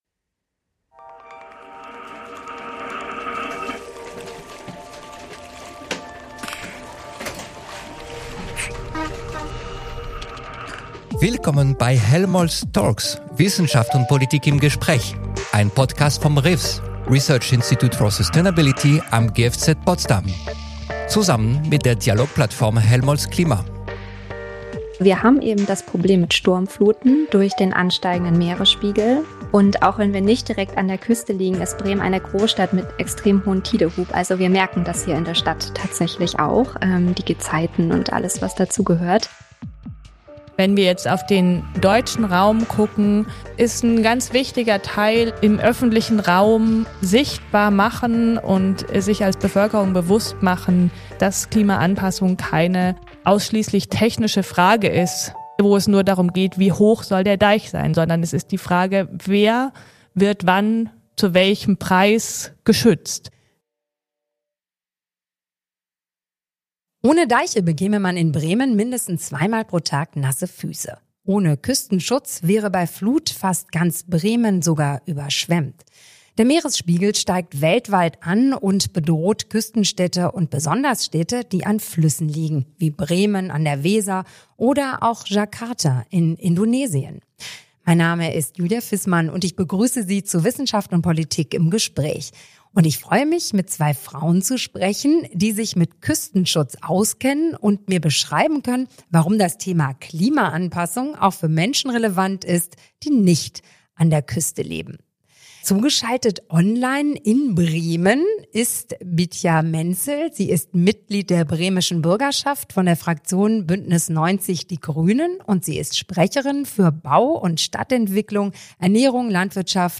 Leben am Deich: Alltag und Zukunft in Küstenstädten ~ Helmholtz Talks - Wissenschaft und Politik im Gespräch Podcast